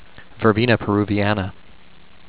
Pronunciation:
ver-bee-na pear-uu-vi-ana